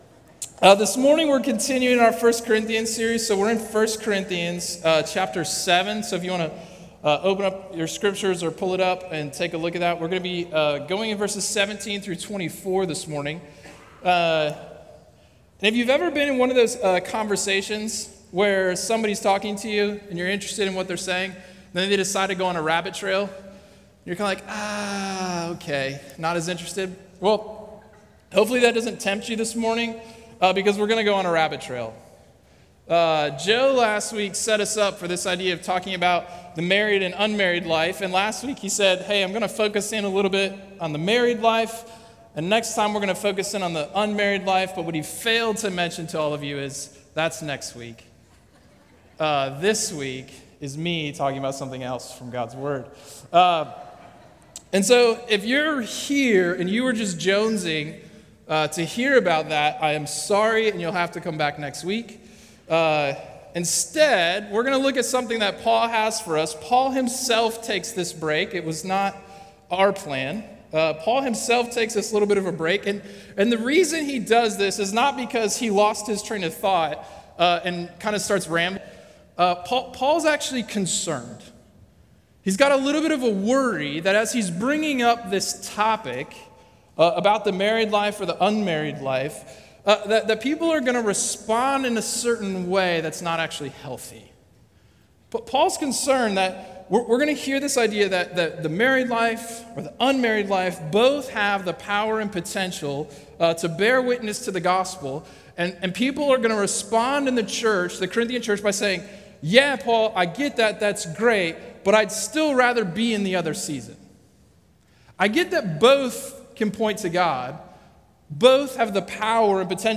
A message from the series "Called Out."
A Sunday morning series on 1 Corinthians at Crossway Community Church.